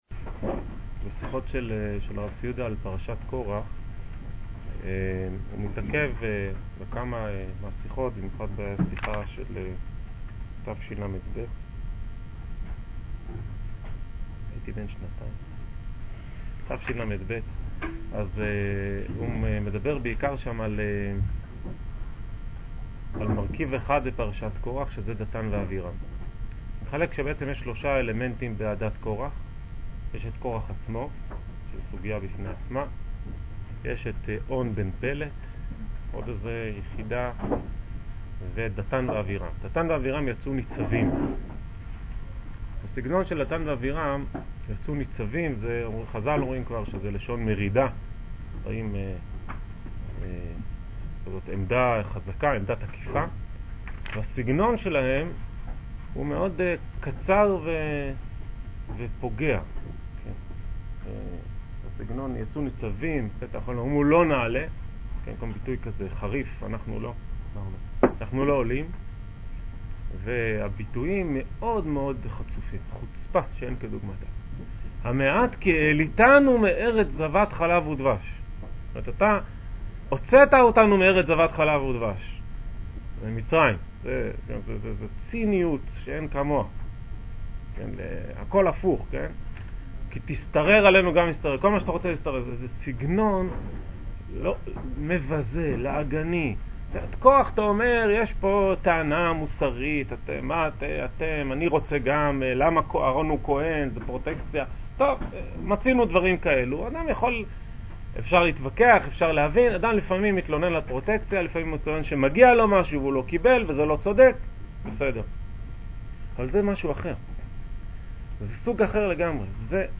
חוצפה-עזות פנים | שיעור כללי - בני דוד - עלי